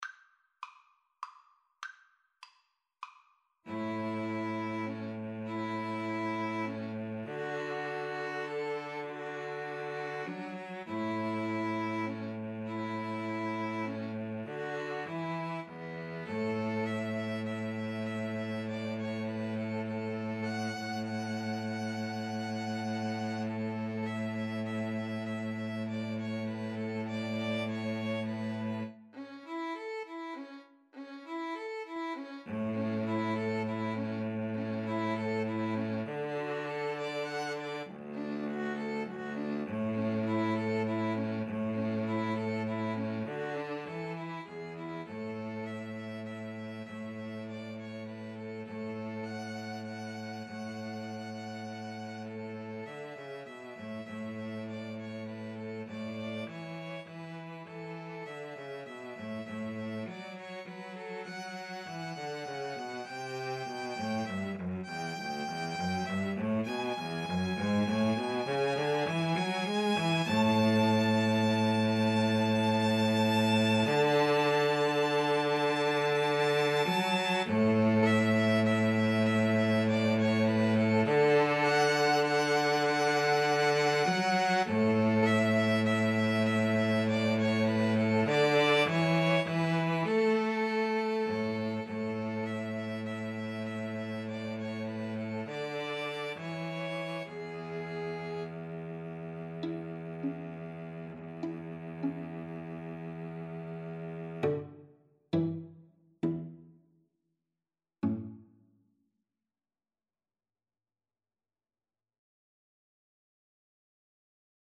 A major (Sounding Pitch) (View more A major Music for 2-Violins-Cello )
Gently = c. 100
Traditional (View more Traditional 2-Violins-Cello Music)